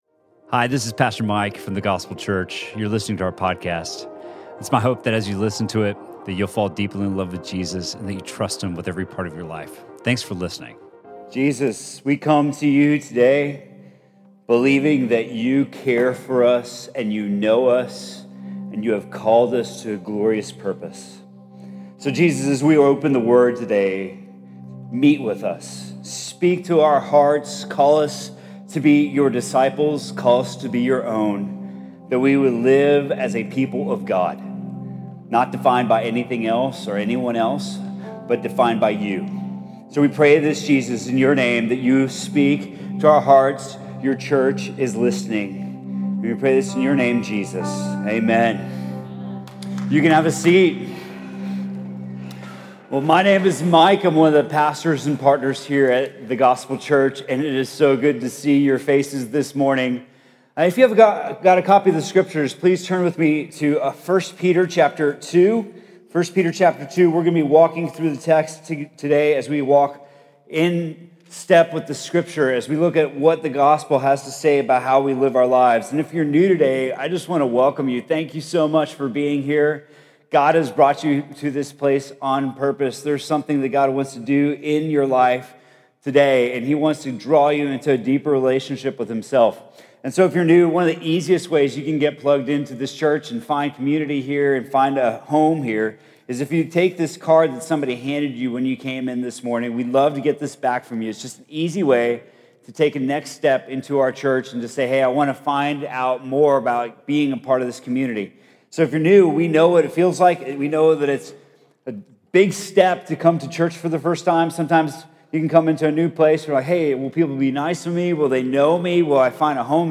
Sermon from The Gospel Church on September 9th, 2018.